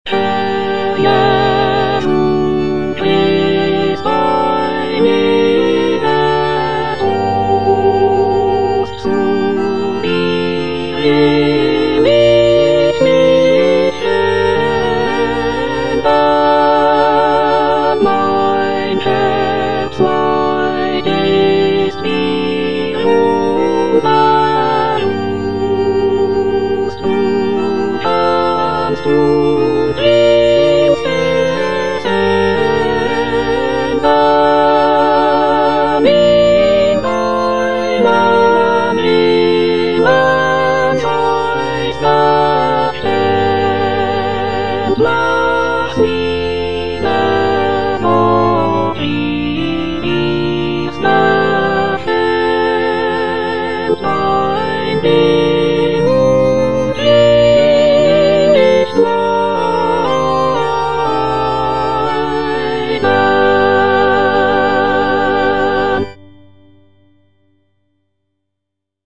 Cantata
Soprano (Emphasised voice and other voices) Ads stop